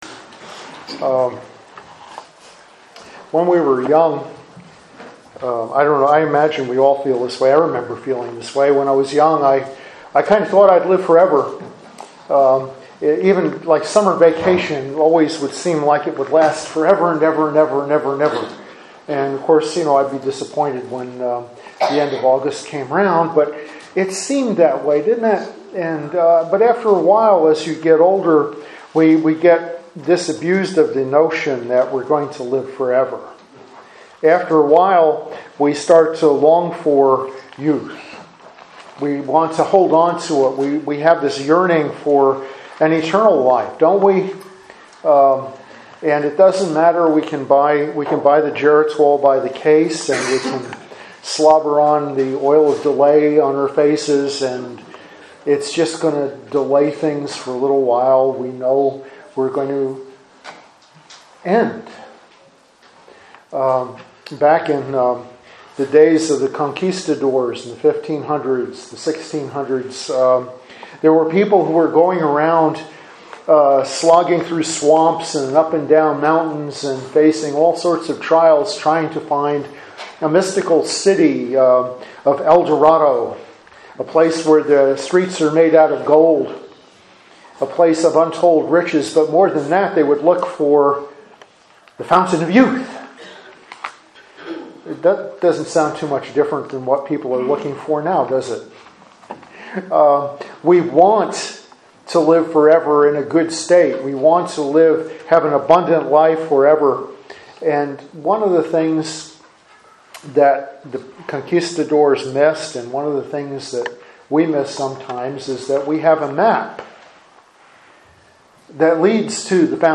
Sermons on Topics
Topical Sermons